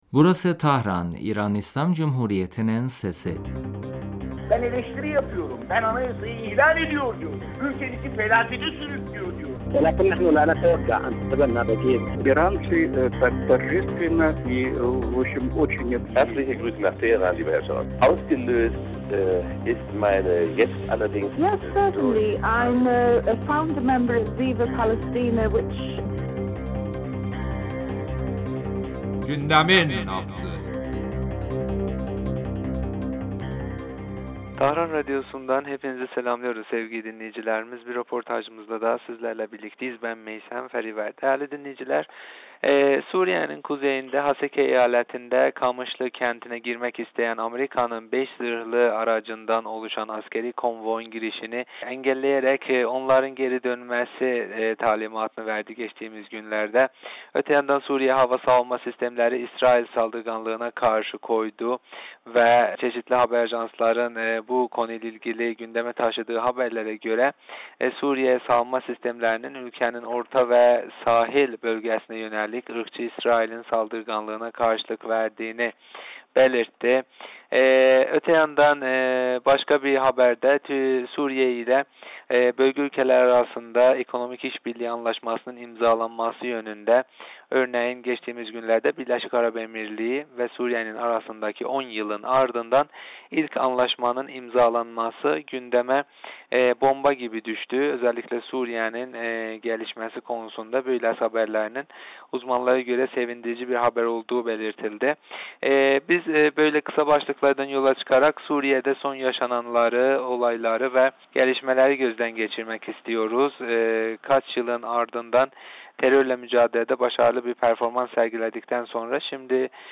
Siyasi meseleler uzmanı sn.